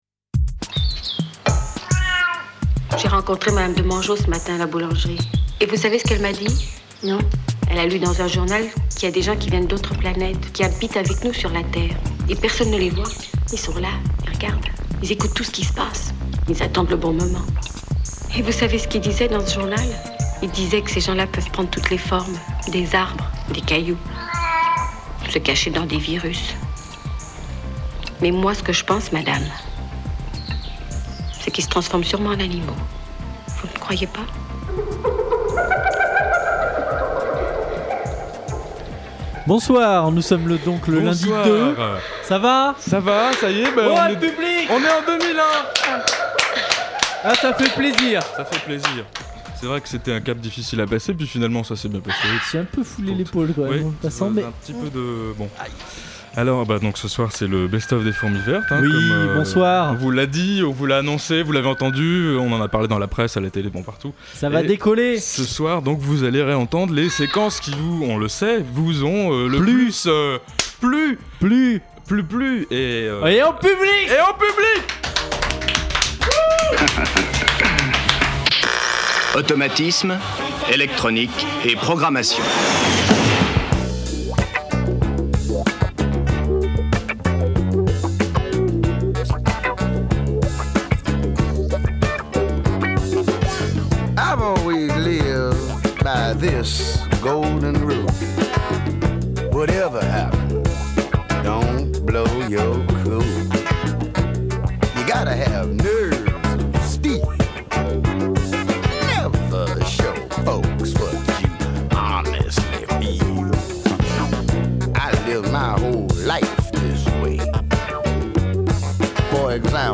diffusion originale : radio Pulsar, le 02.01.2001 de 19h à 20h spécificités : direct qui part en live